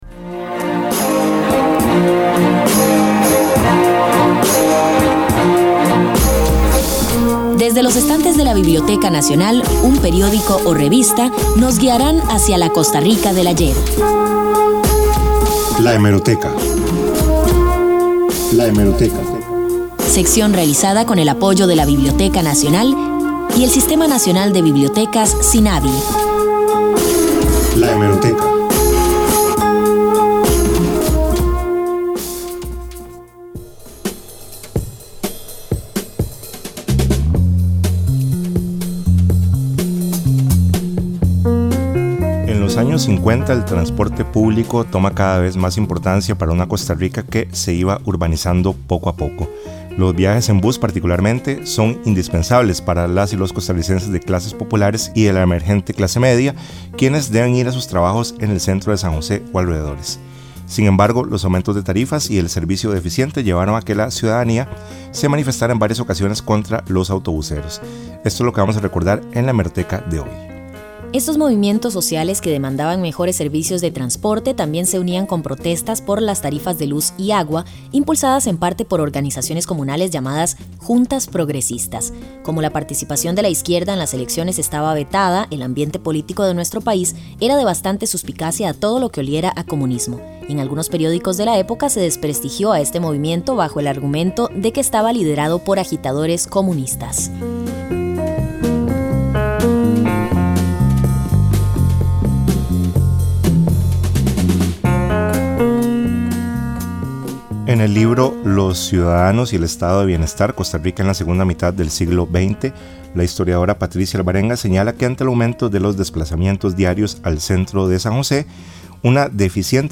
• Sección de la Biblioteca Nacional en el Programa Pistas Sonoras de Radio Universidad, transmitido el 10 de noviembre del 2018. Este espacio es una coproducción de las Radios de la Universidad de Costa Rica y la Biblioteca Nacional con el propósito de difundir la cultura costarricense.